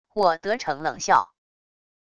我得逞冷笑wav音频